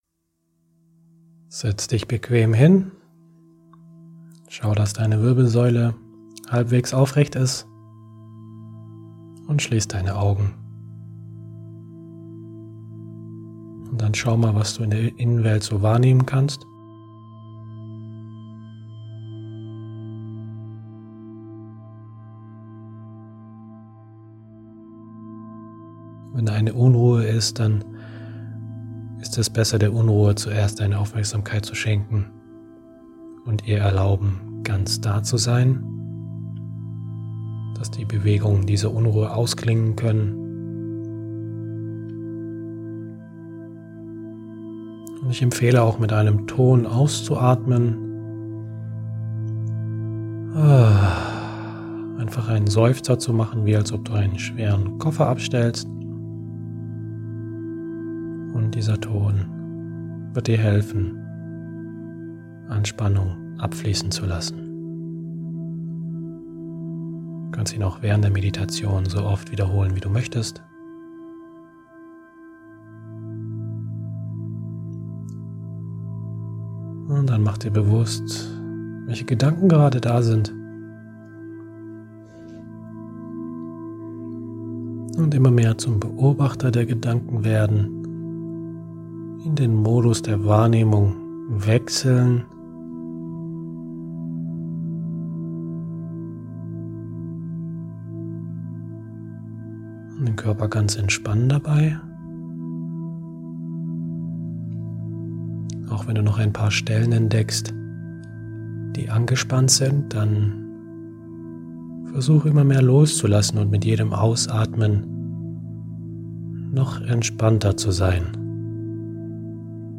Durch die geführte Meditation erfährst du automatisch, wann die 10 Minuten vorbei sind. 5) Setze dich bequem hin.
Meditation-Challenge-Woche-3-Musik.mp3